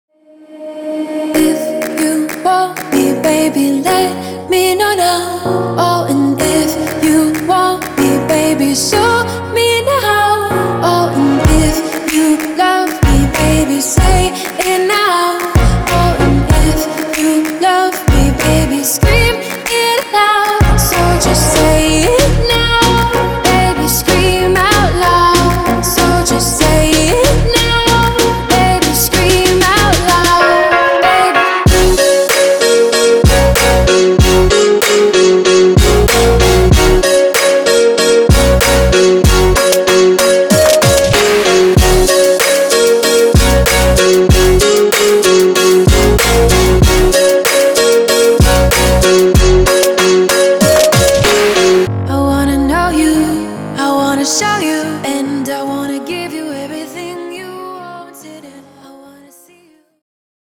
Vocal hooks and all other sounds.